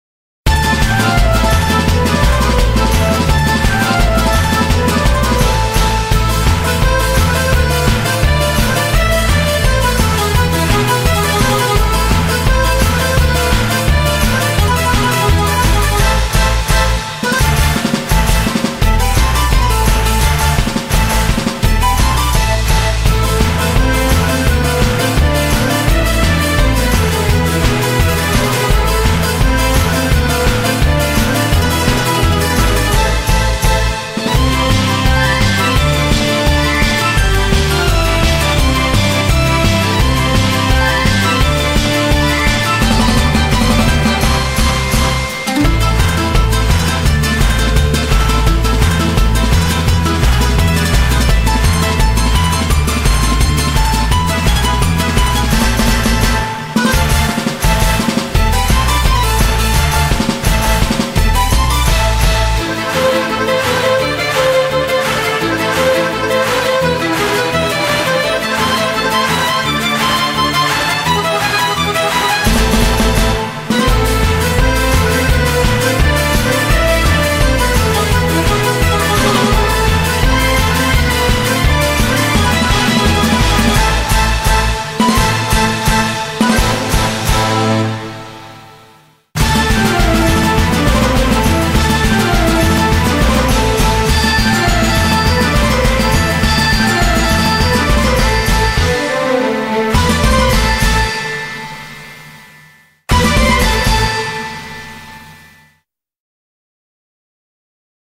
BPM170-240
Audio QualityPerfect (Low Quality)